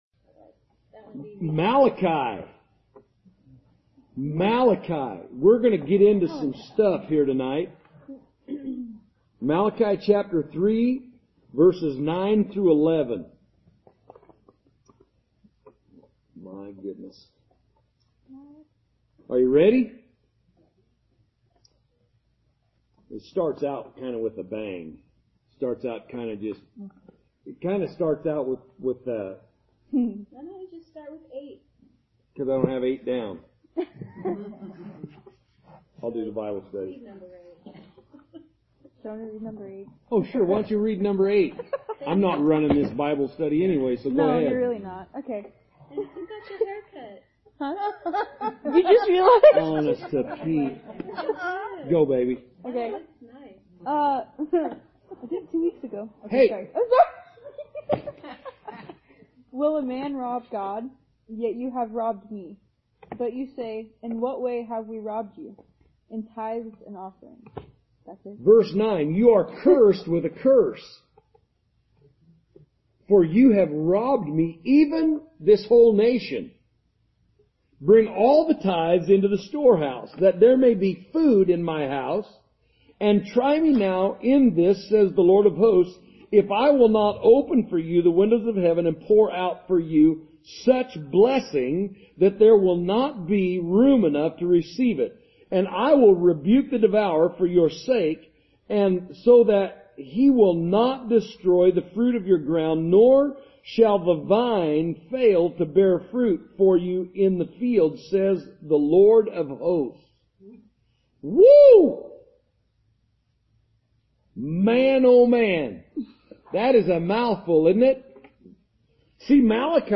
Sunday Service January 26, 2020